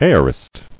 [a·o·rist]